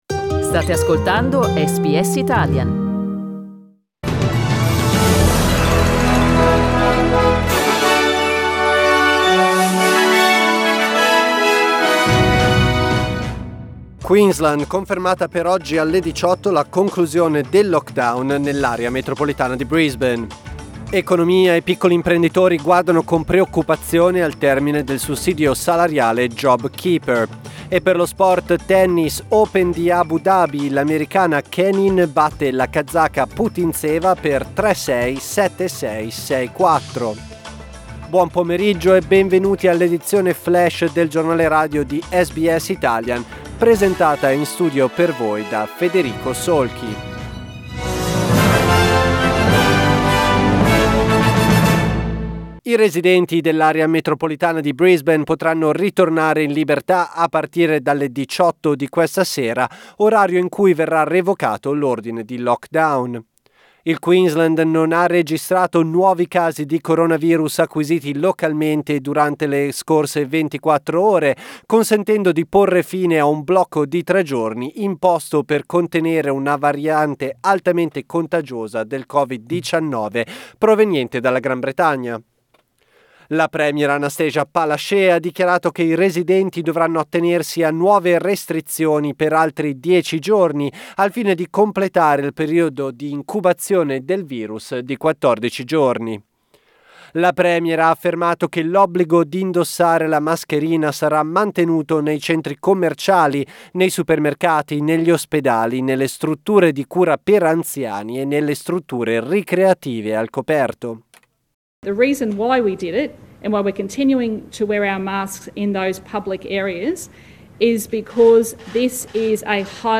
News Flash Monday 11 January 2021
Our news update in Italian.